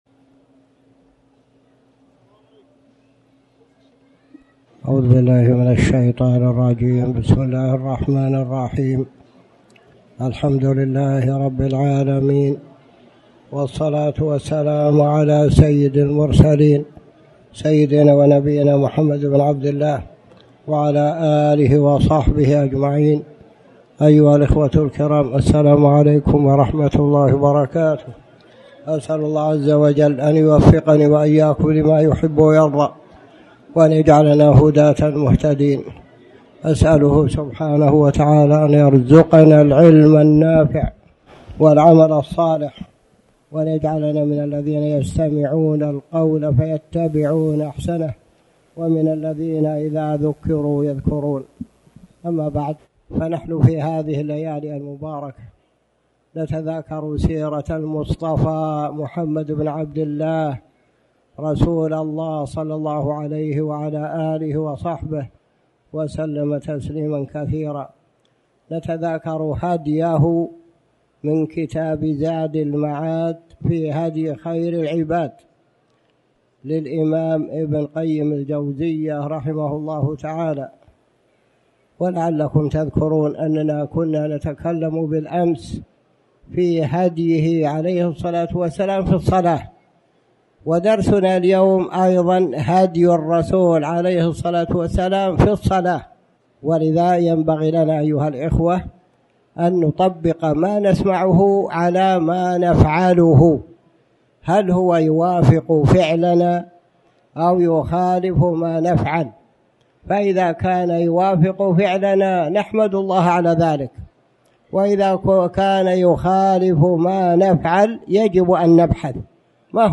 تاريخ النشر ١٨ رجب ١٤٣٩ هـ المكان: المسجد الحرام الشيخ